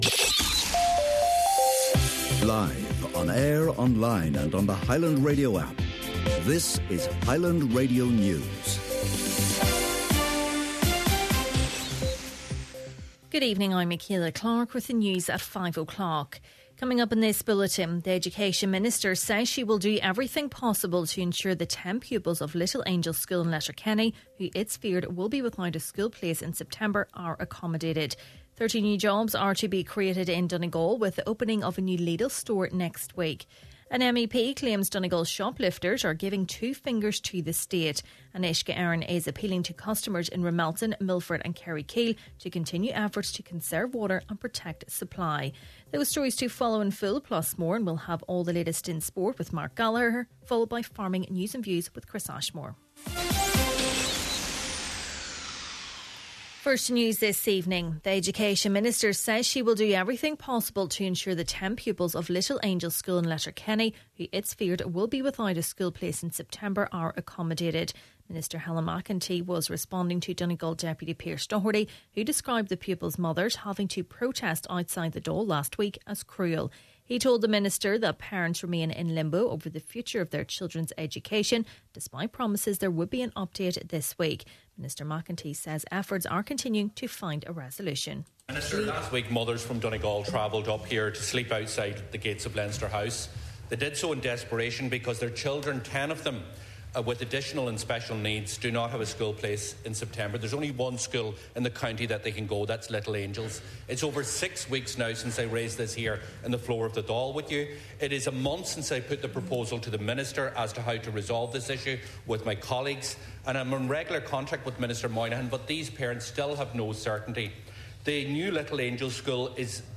Main Evening News Sport, Farming News and Obituaries – Thursday, April 10th